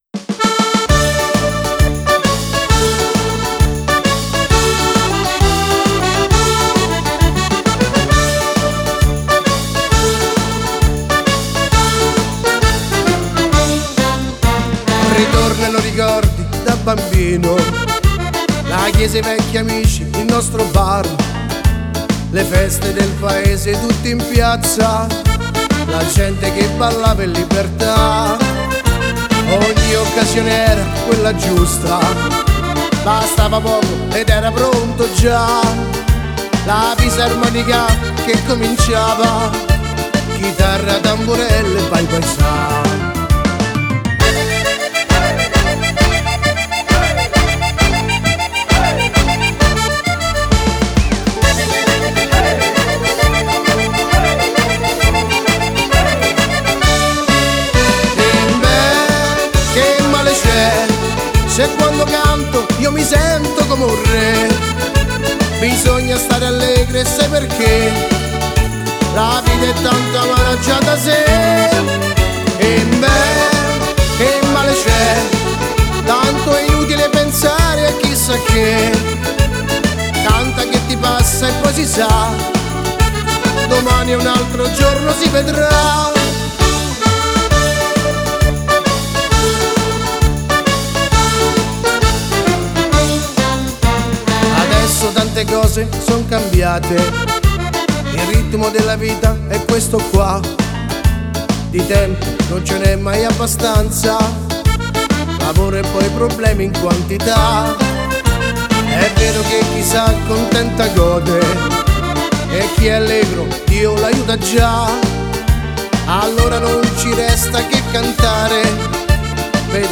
Tarantella